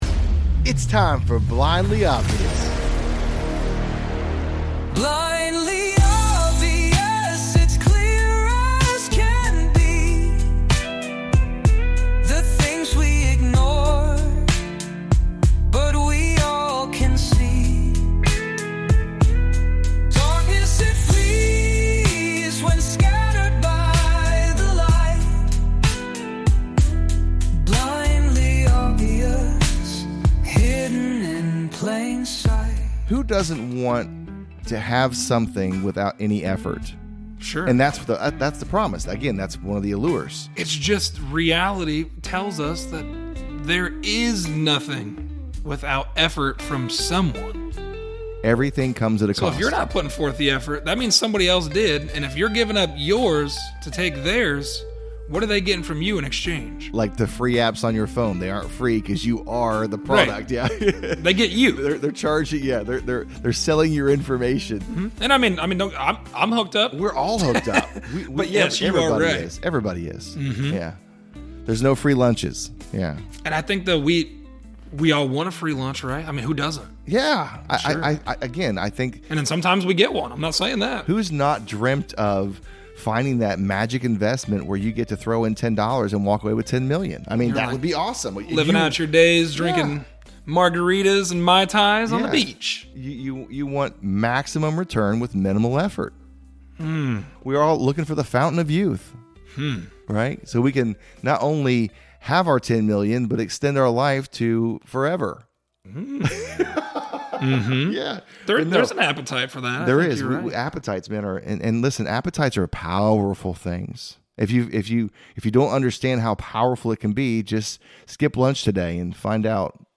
A conversation on our appetite. What are we feeding on - something that keeps us empty and looking for more, or something that keeps us full & satisfied?